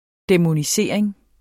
Udtale [ dεmoniˈseˀɐ̯eŋ ]